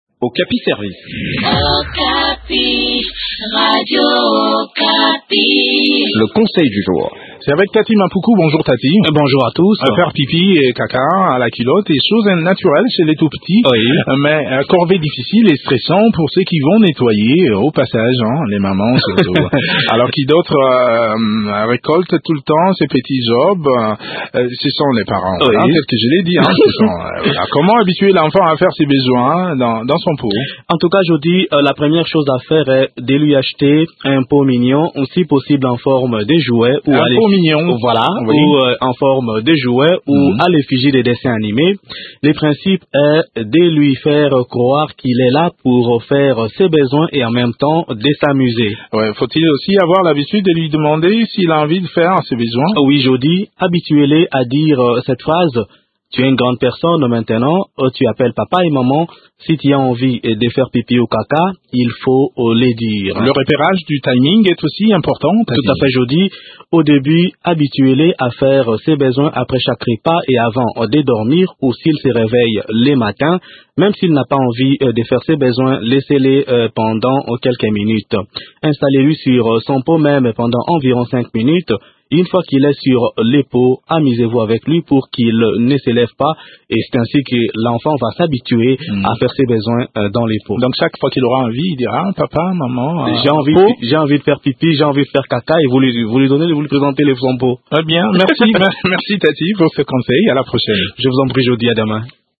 Découvrez des astuces dans cette chronique